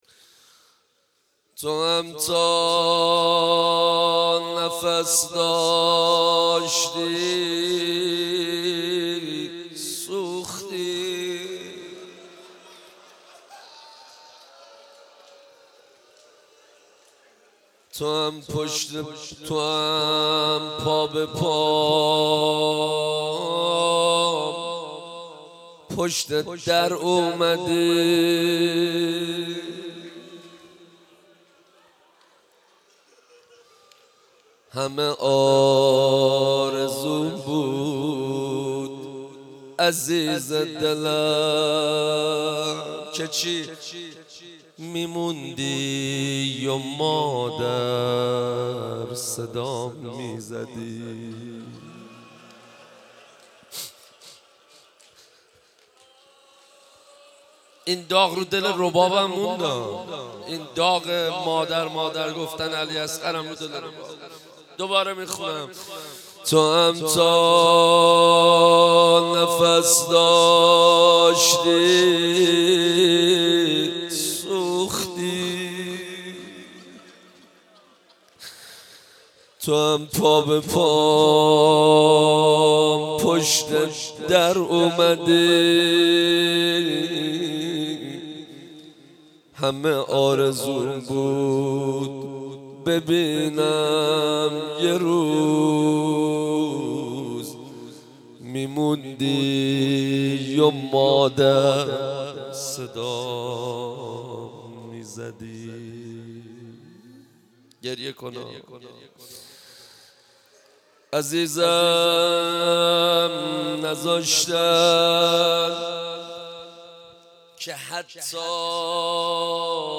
فاطمیه 95 - روضه حضرت زهرا سلام الله علیها